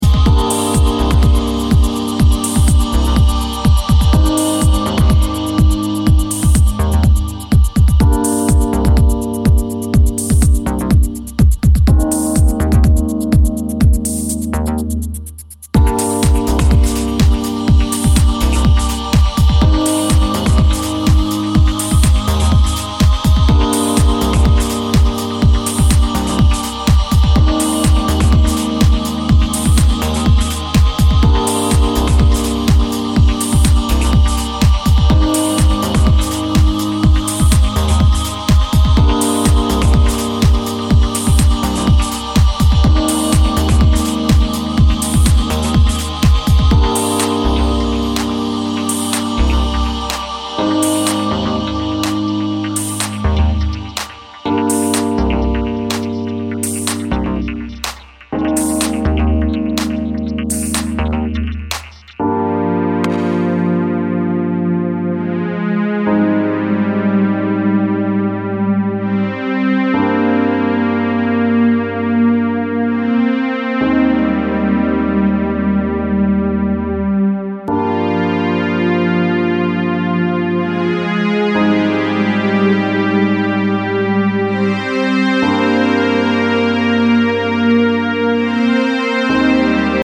as part of a five-track EP of deep house and electro